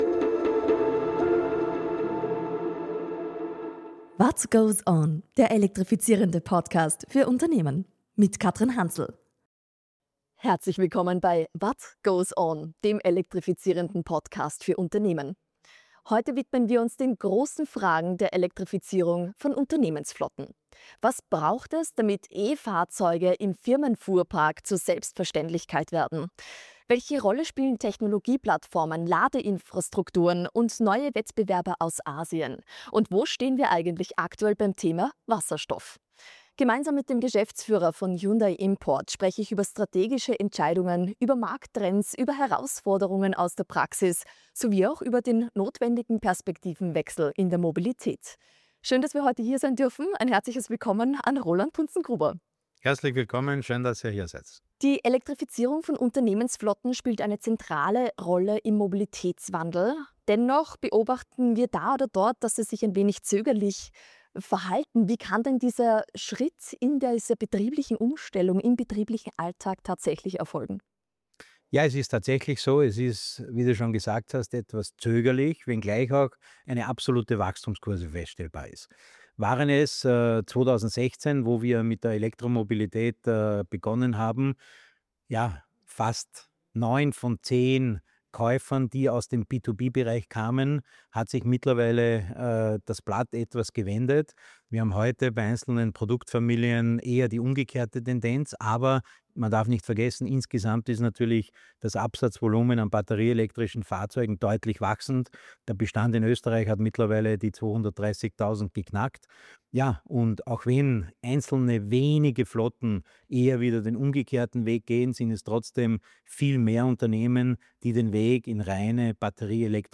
Ein Gespräch für alle, die Elektromobilität unternehmerisch, strategisch und zukunftsorientiert denken wollen.